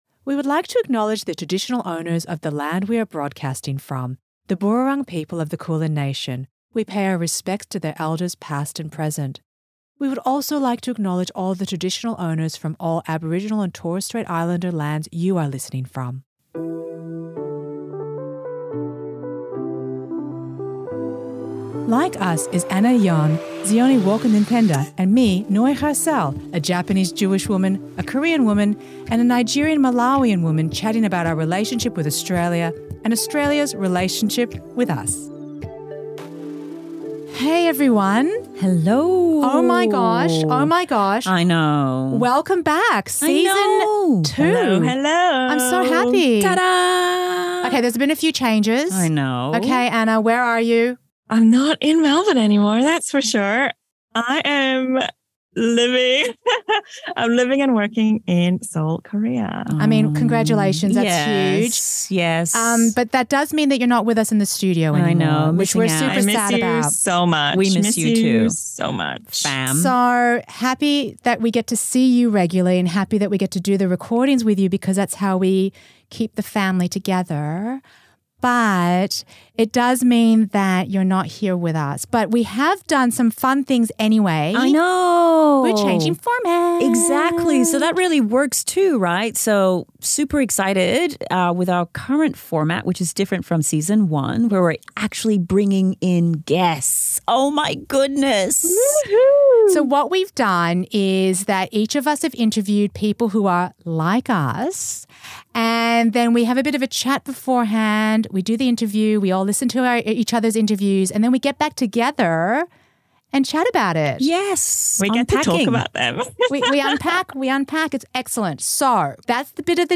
Then they share the interview with each-other and regroup to unpack.